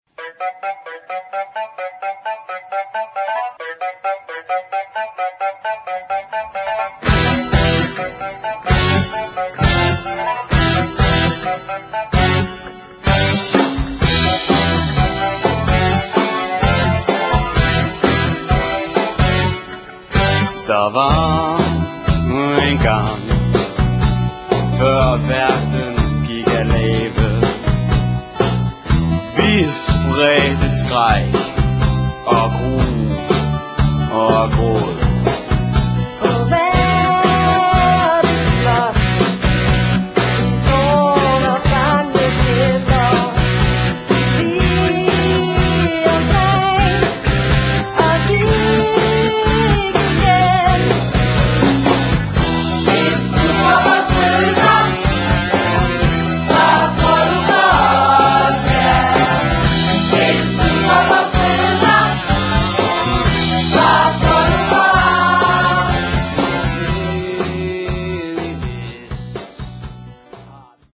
Der er både pop, rock og rumba.